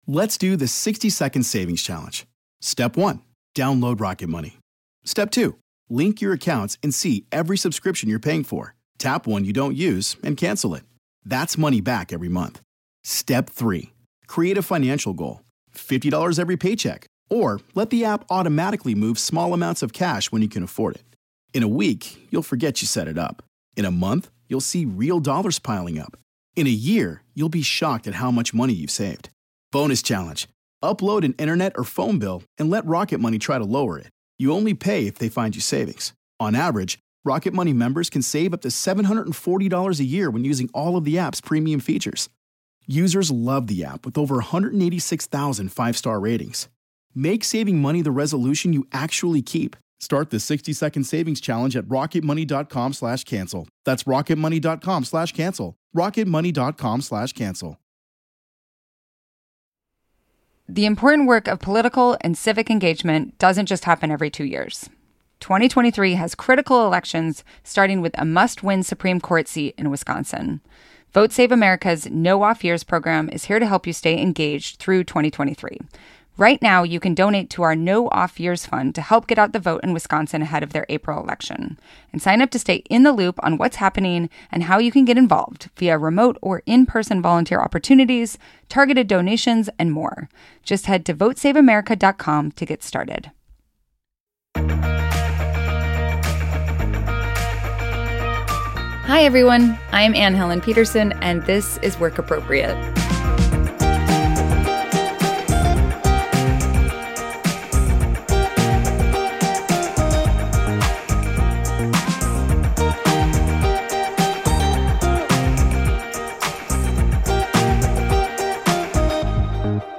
Comedian and writer Josh Gondelman joins host Anne Helen Petersen to answer questions from listeners who are struggling to feel confident at work.